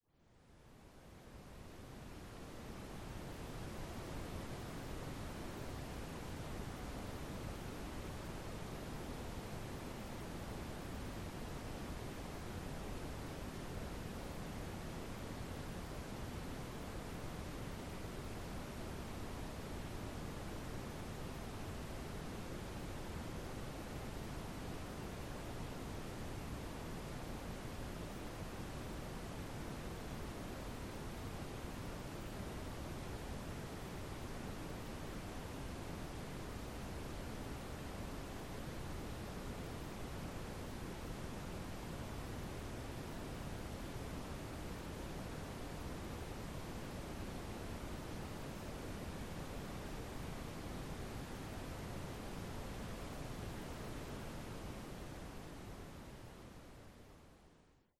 Calming nature recordings and ambient soundscapes.
Distant Thunderstorm
Duration: 0:58 · Type: Nature Recording · 128kbps MP3
Thunderstorm_Distant.mp3